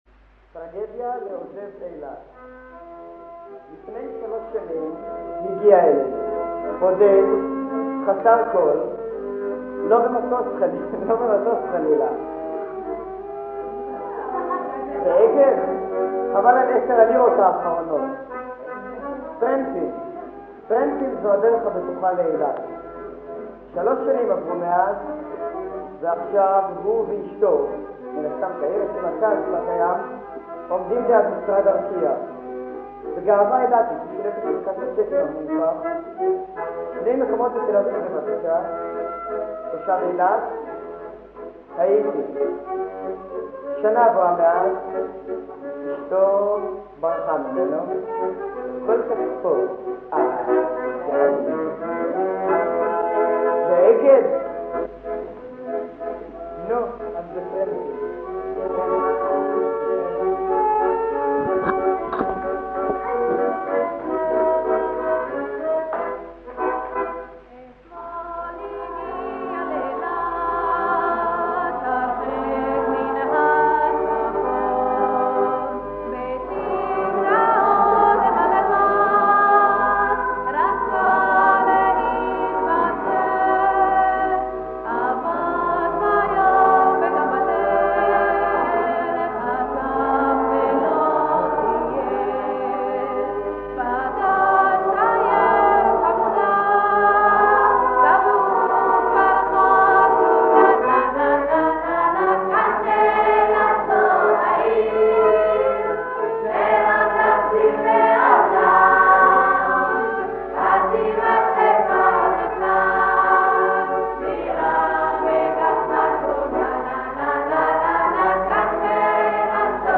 ההקלטות, על סלילים שנשמרו 40 שנה, נשמעות קצת עתיקות, אבל אי אפשר לפספס את הקולות, האקורדיאון והראשוניות!